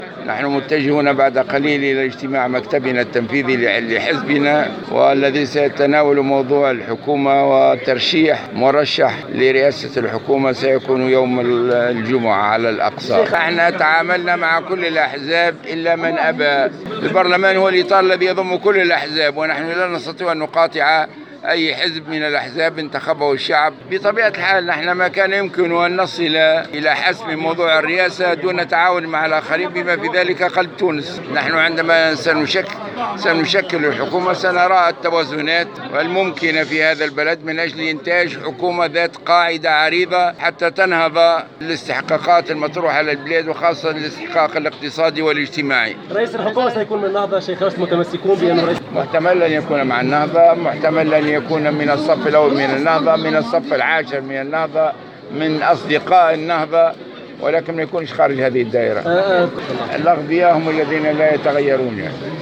وقال الغنوشي في أول تصريح له عقب انتخابه رسميا رئيسا للبرلمان، أن النهضة ستقدم مرشحها لرئاسة الحكومة يوم الجمعة على أقصى تقدير مشيرا الى انهم تعاملوا في التفاوض مع كل الاحزاب الا من أبى . وافاد بأن رئيس الحكومة الجديد من المحتمل أن يكون من النهضة سواء من الصف الأوّل أو من الصف العاشر او من أصدقاء النهضة ولن يكون خارج هذه الدائرة”.